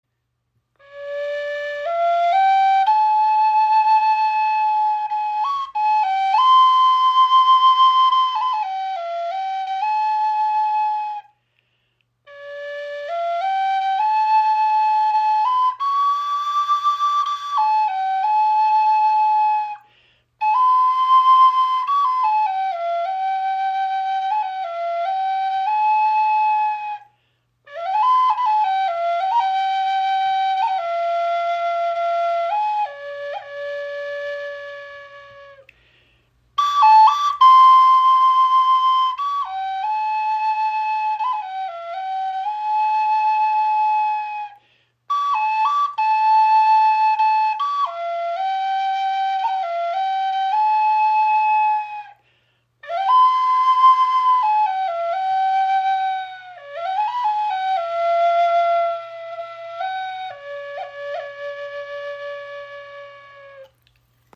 EarthTone Spirit Flöten | Handgefertigt | hohes D in 432 Hz
Unsere EarthTone Spirit Flöte in hohes D-Moll (432 Hz) ist handgefertigt aus einem Stück spanischer Zeder. Das weiche Holz verleiht ihr eine warme, resonante Klangstimme.